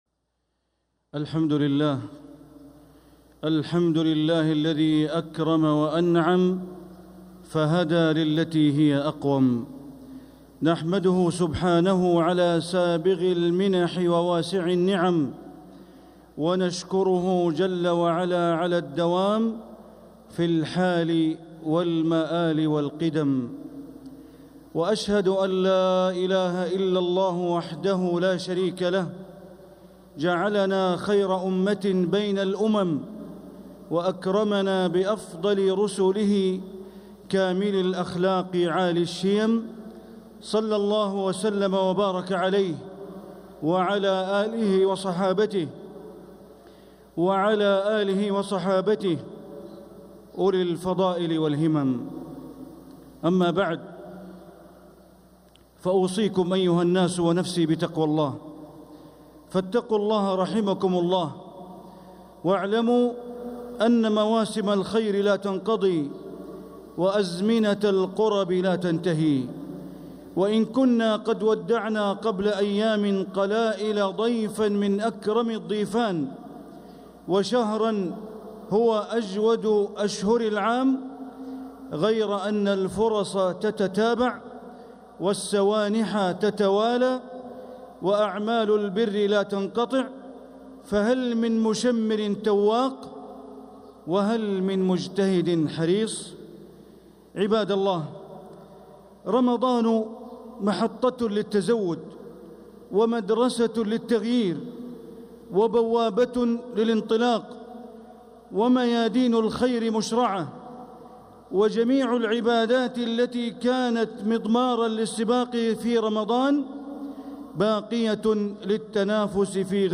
خطبة الجمعة 6 شوال 1446هـ | Khutbah Jumu’ah 4-4-2025 > خطب الحرم المكي عام 1446 🕋 > خطب الحرم المكي 🕋 > المزيد - تلاوات الحرمين